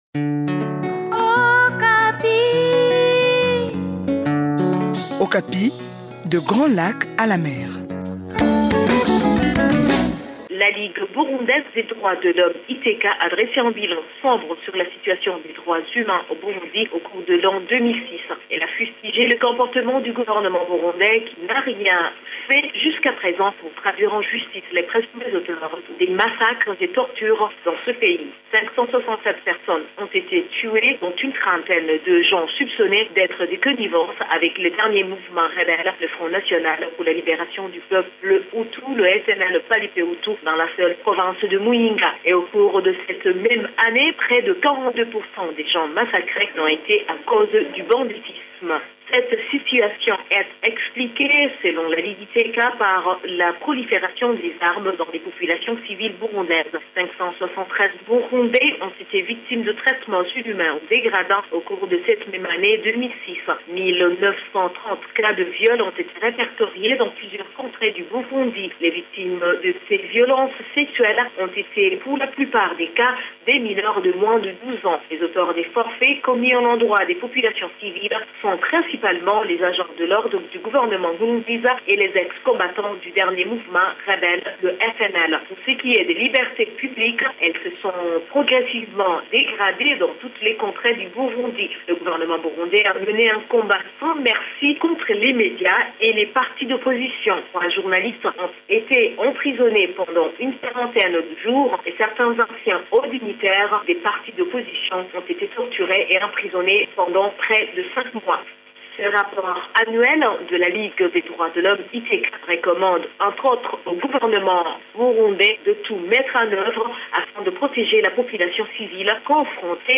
Plus de précisions avec notre correspondant à Bujumbura